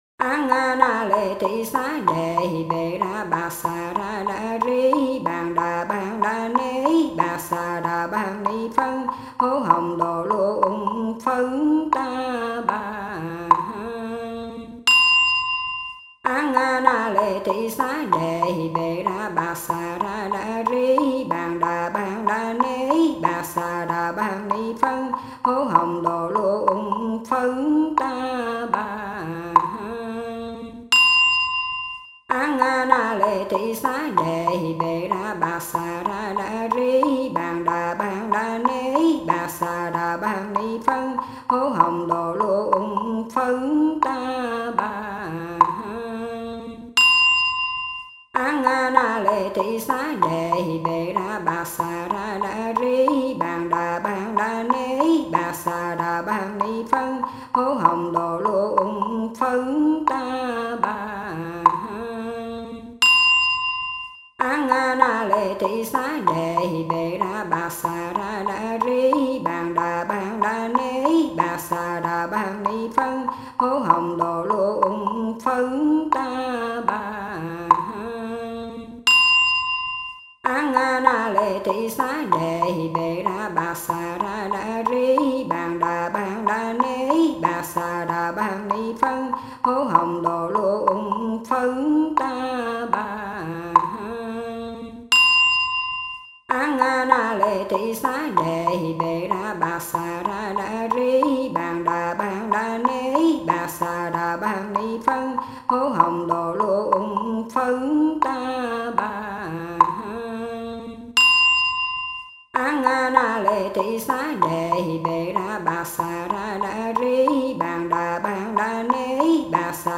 108-lang-nghiem-tam-chu-co-van-dieu.mp3